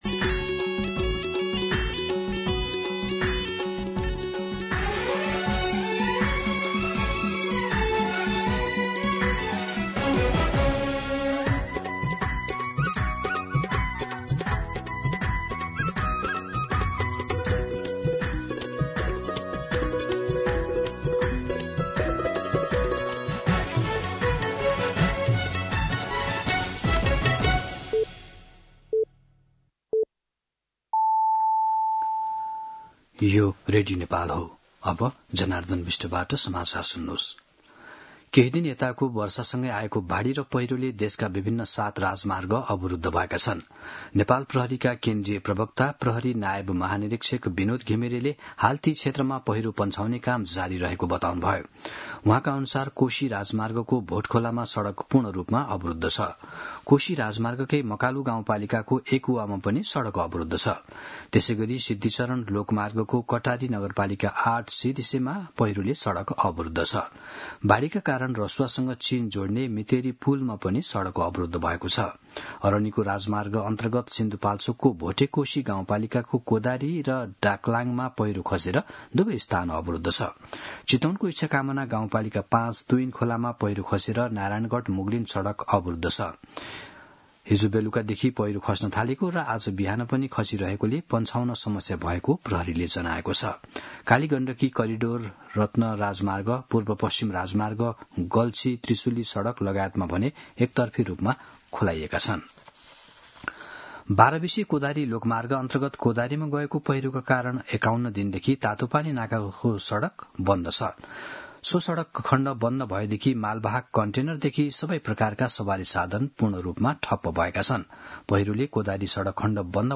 दिउँसो १ बजेको नेपाली समाचार : ४ असोज , २०८२
1-pm-Nepali-News-2.mp3